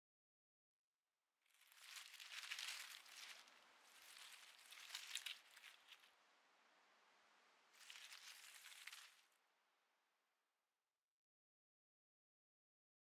leaves.ogg